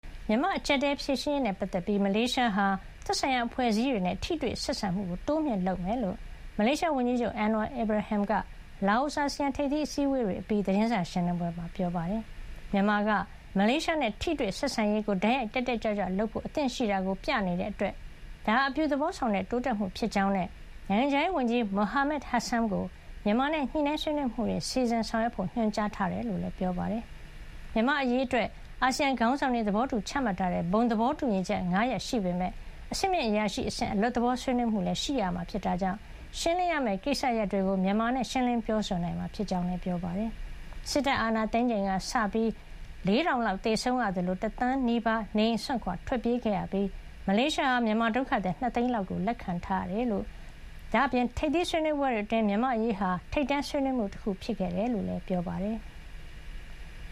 မြန်မာ့အကြပ်အတည်း ဖြေရှင်းရေးနဲ့ ပတ်သက်ပြီး မလေးရှားနိုင်ငံဟာ သက်ဆိုင်ရာအဖွဲ့အစည်းတွေနဲ့ ထိတွေ့ဆက်ဆံမှု တိုးမြှင့်လုပ်ဆောင် သွားမှာ ဖြစ်ကြောင်း မလေးရှားဝန်ကြီးချုပ် Anwar Ibrahim က လာအိုမှာကျင်းပတဲ့ အာဆီယံထိပ်သီး အစည်းဝေးနဲ့ ဆက်စပ်အစည်းဝေး တွေအပြီး သတင်းစာရှင်းလင်းပွဲမှာ ပြောပါတယ်။